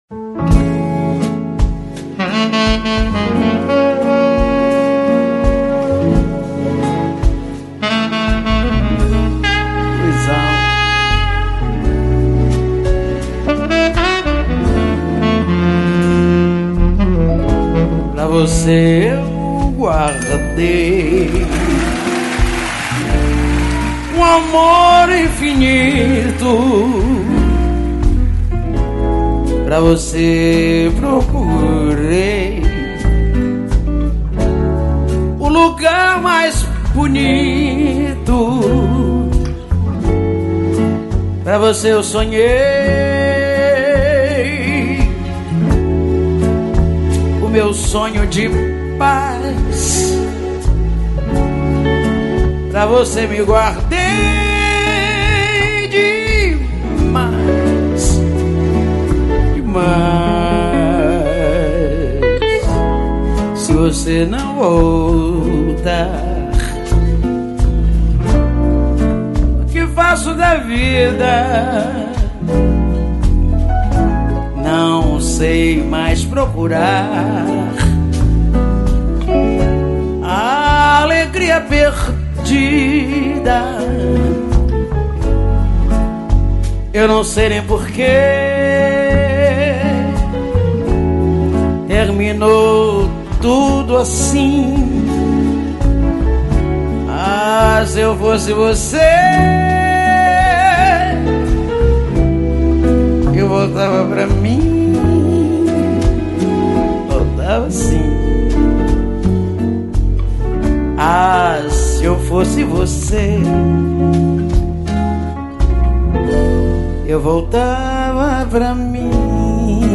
Sambas